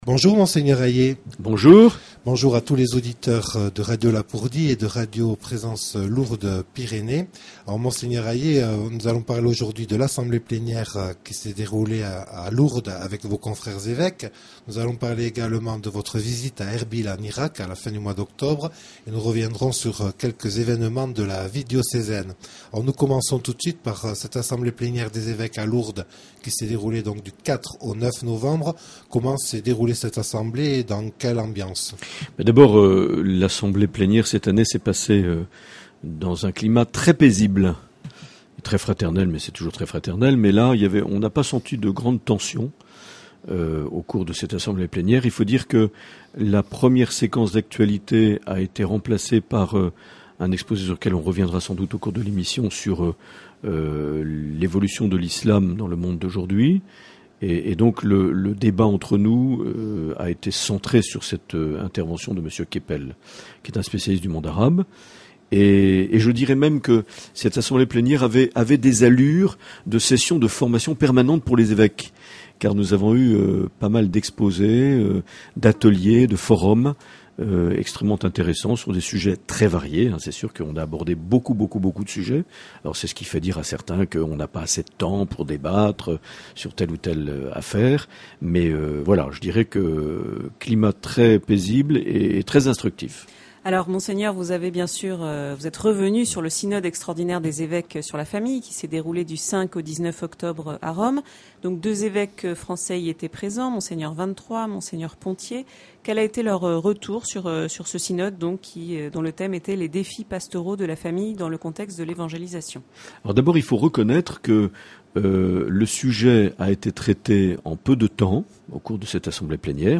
Les entretiens
Une émission présentée par Monseigneur Marc Aillet